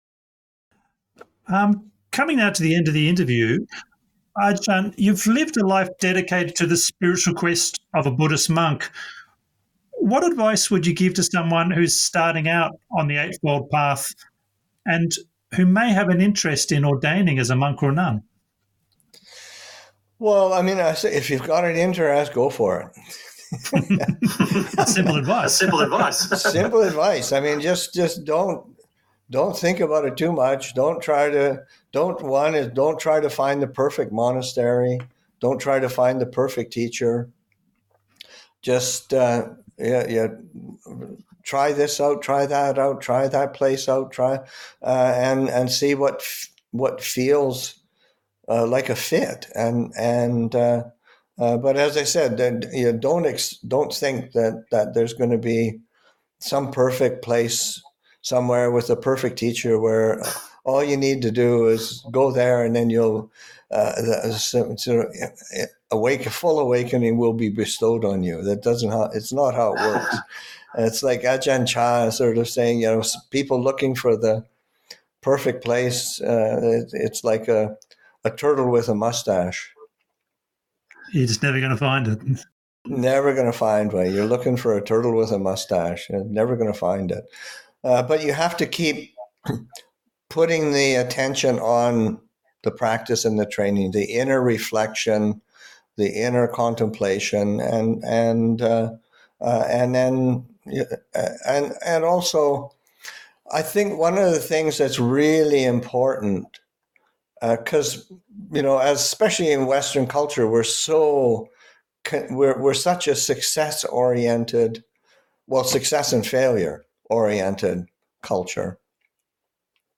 Q&A at Sudhana Center – Jul. 12, 2023